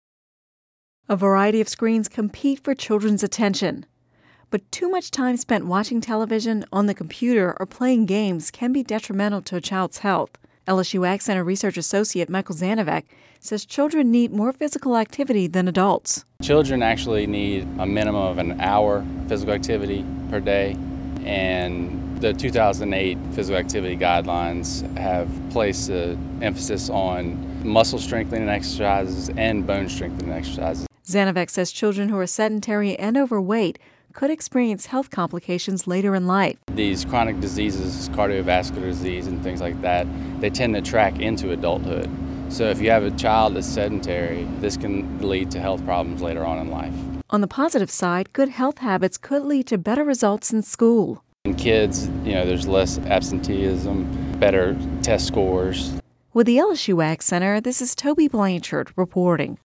(Radio News 02/07/11) A variety of screens compete for children’s attention. But too much time spent watching television, on the computer or playing games can be detrimental to a child’s health.